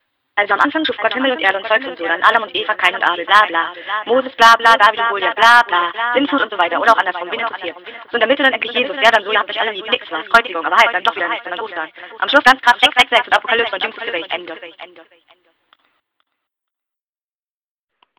Lautsprecher trans.png   Dieser Beitrag existiert im Rahmen des Projekts Gesungene Kamelopedia auch als Audiodatei.